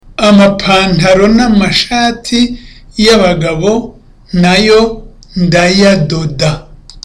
(With confidence.)